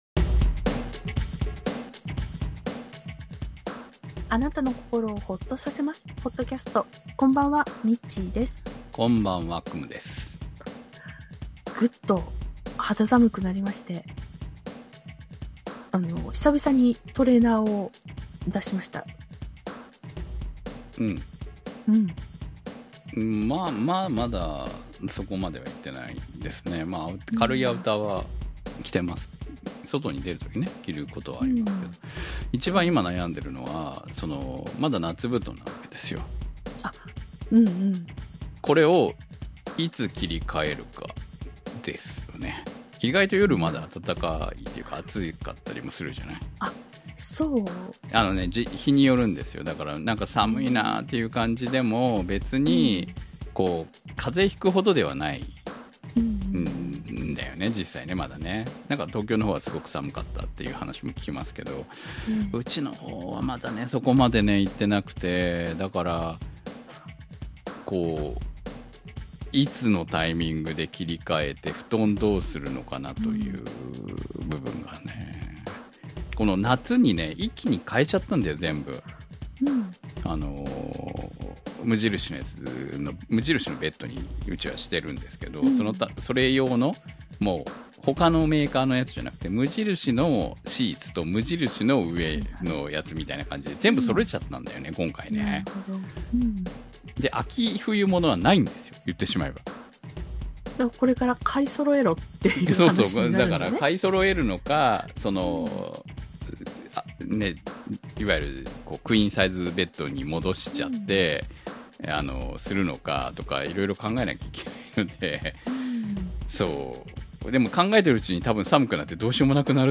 今回は秋らしさも含め話題盛り沢山な、フリートーク回です。 季節が変わるとそれは物欲の季節。今季買ったものを紹介。